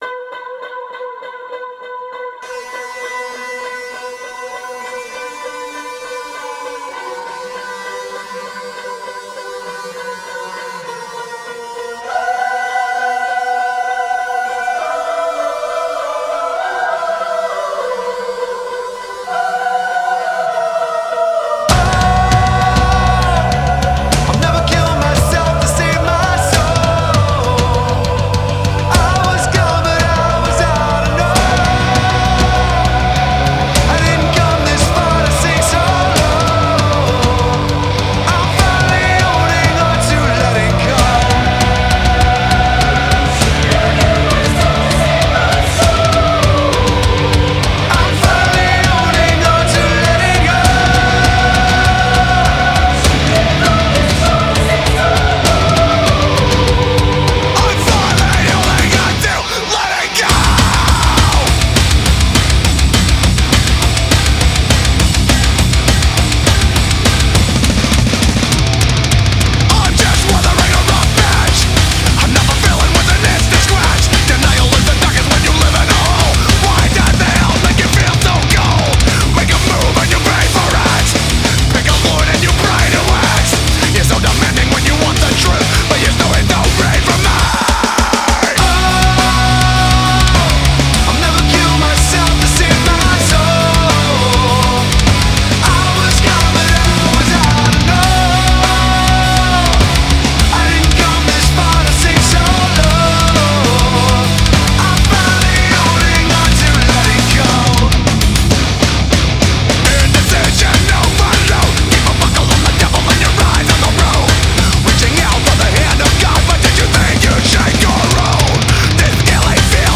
genre:Nu metal